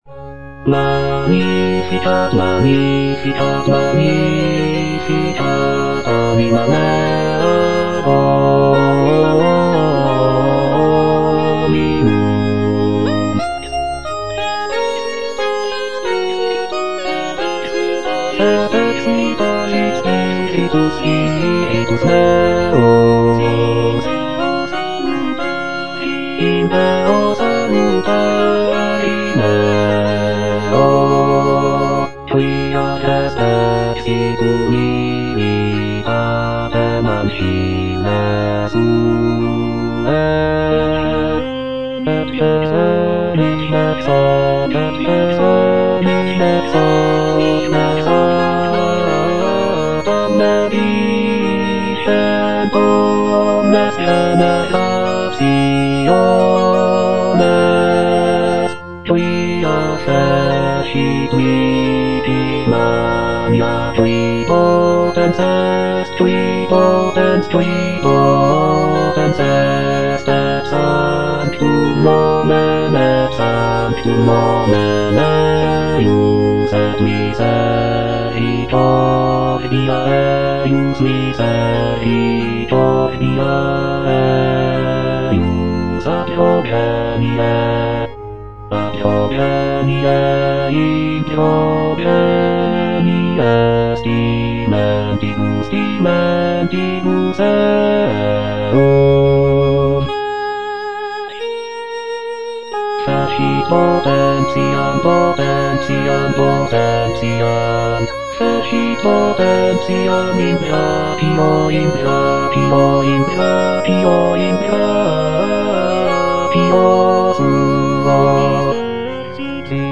J. PACHELBEL - MAGNIFICAT IN D (EDITION 2) (A = 415 Hz) Bass (Emphasised voice and other voices) Ads stop: